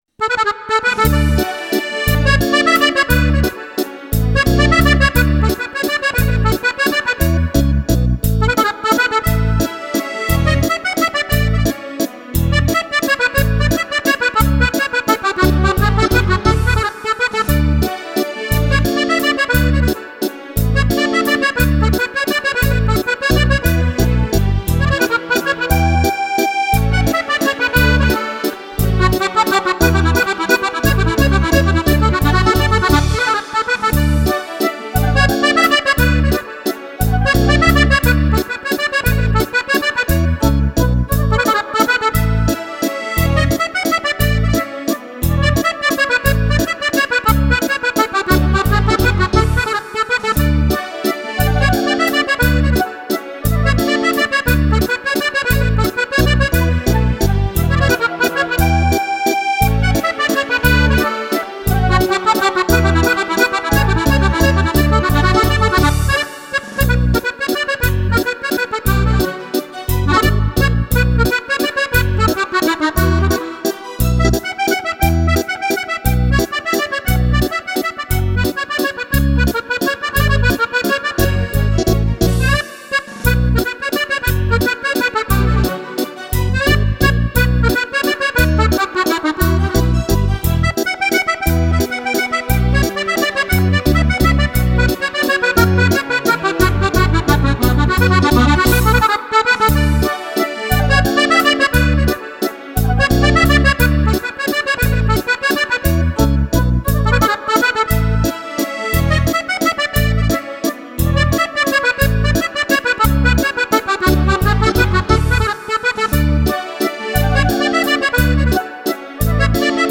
Valzer
ballabili per fisarmonica